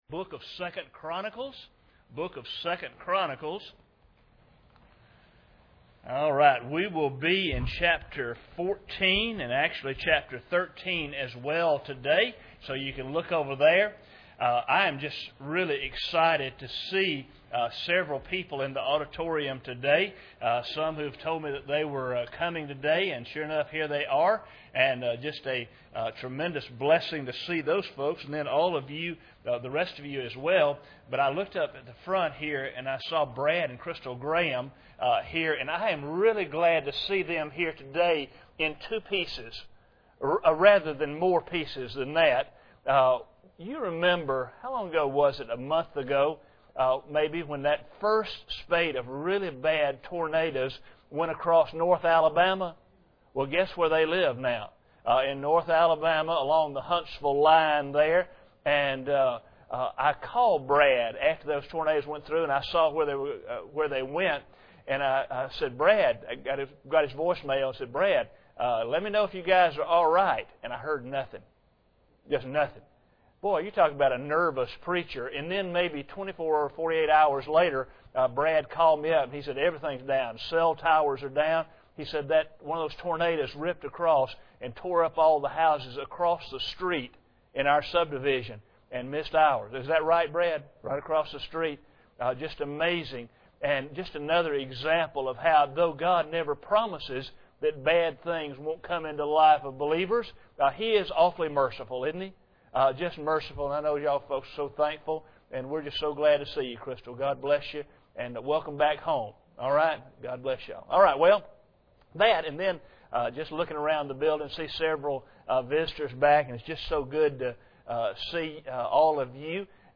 2 Chronicles 13:1-3 Service Type: Sunday Morning Bible Text